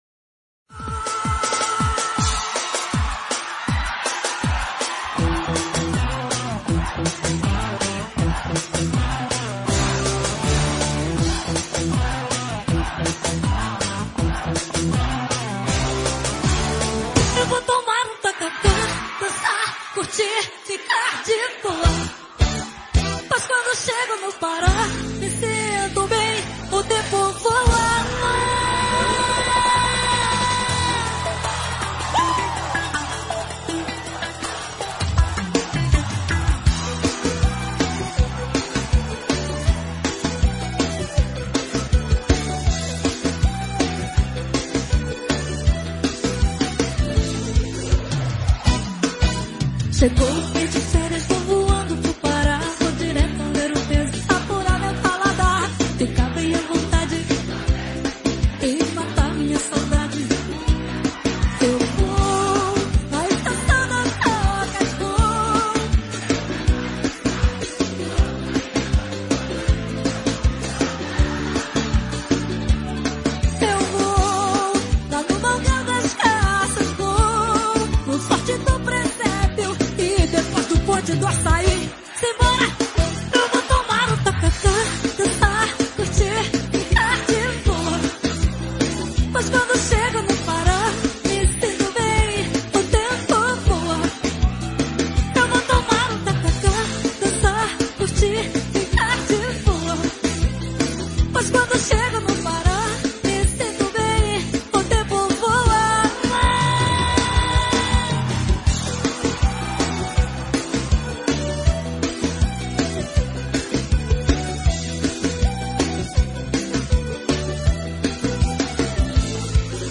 Brega e Forro